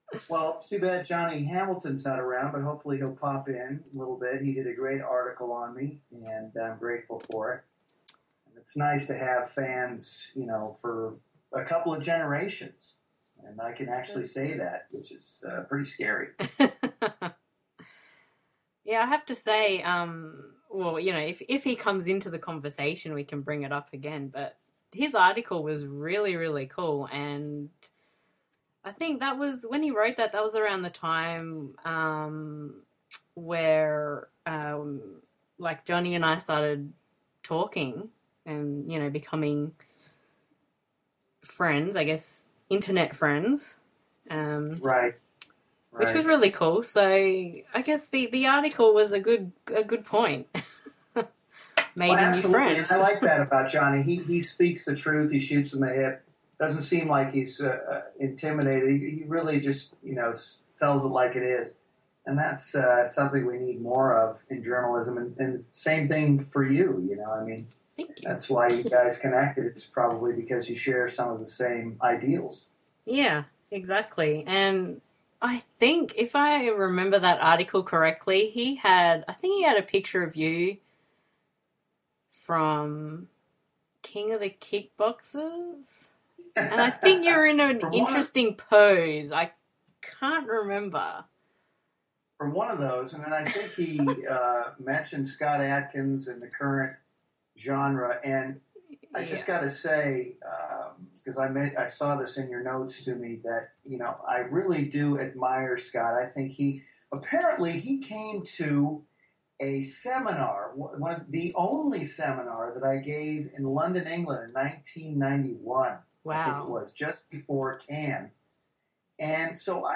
Preview: Interview with Loren Avedon